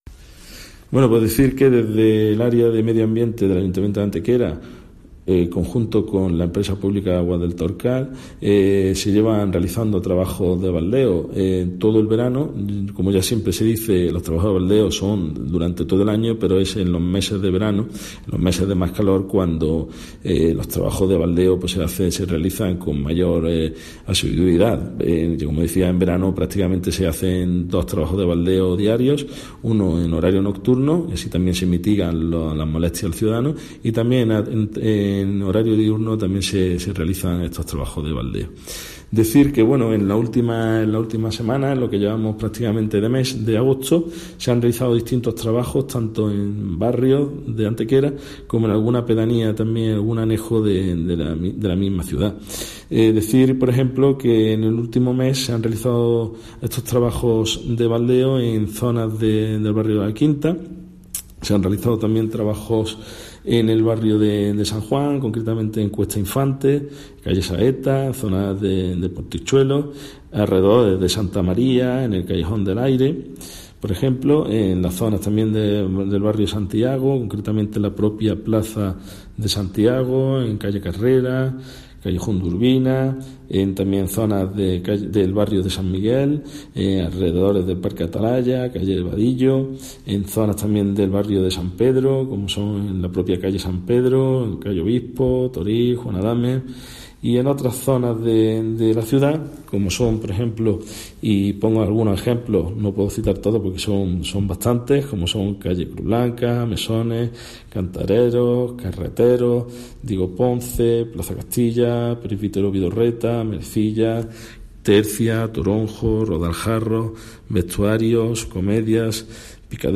Cortes de voz J.Alvarez 1294.28 kb Formato: mp3